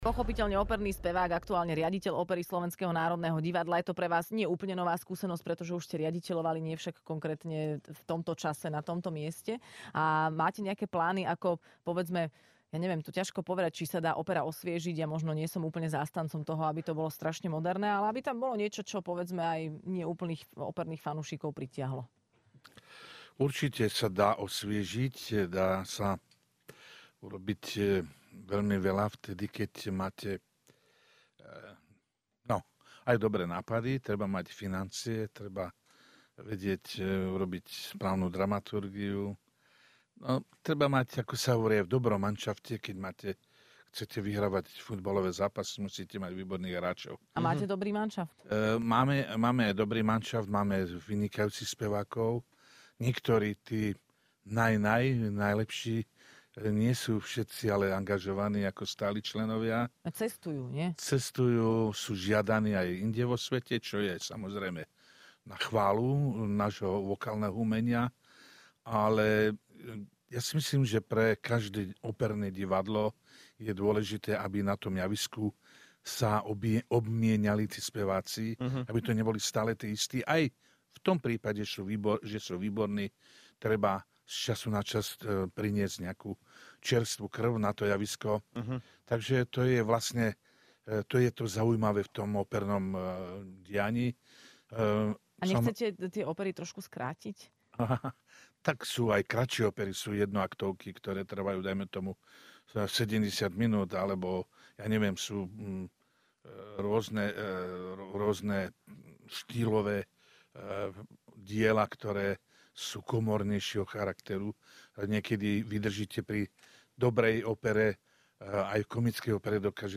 Hosťom v Rannej šou bol spevák Peter Dvorský.